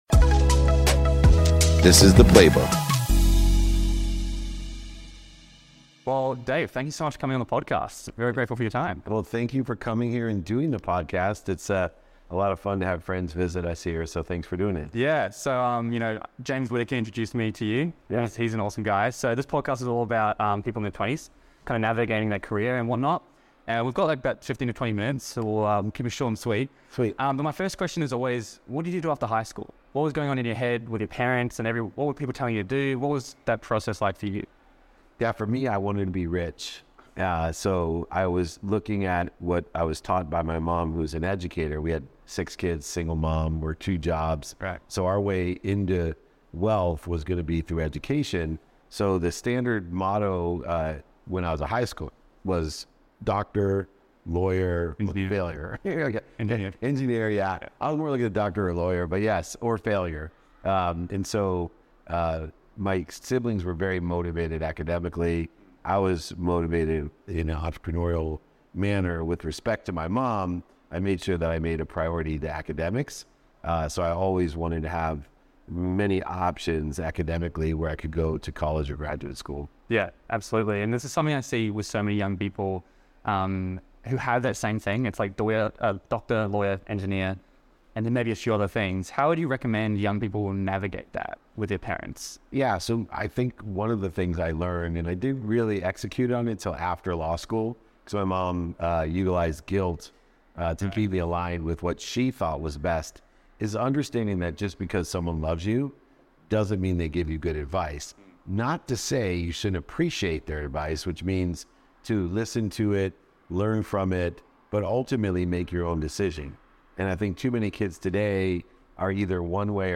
Today’s episode features a meaningful conversation